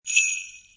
Download Free Jingle Bells Sound Effects